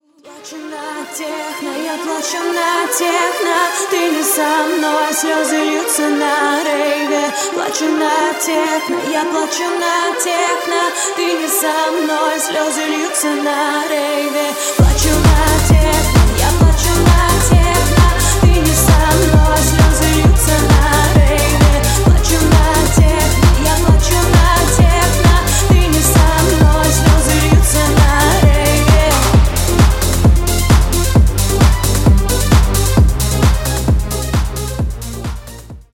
грустные
Synth Pop
house
techno